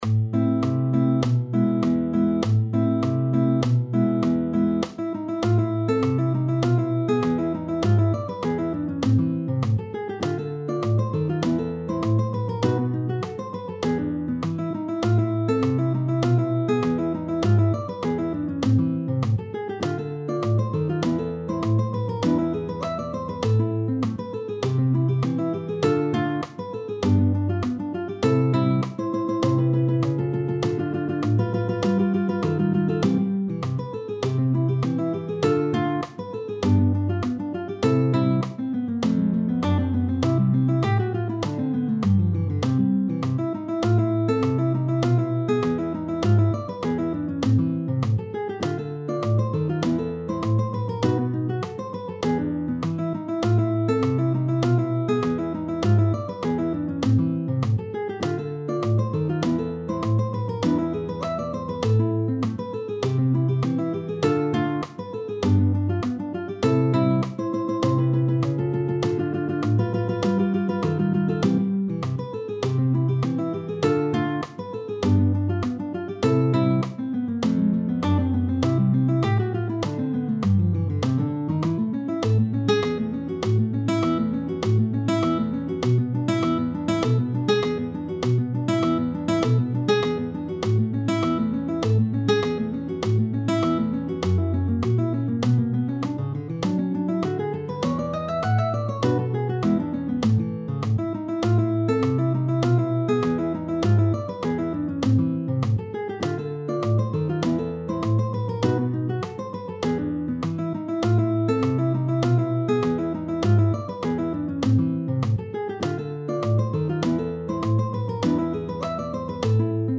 Spartito PER CHITARRA in tabulato e notazione musicale!